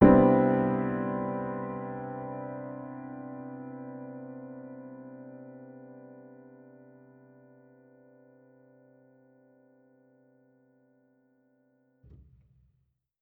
Index of /musicradar/jazz-keys-samples/Chord Hits/Acoustic Piano 2
JK_AcPiano2_Chord-Cmaj9.wav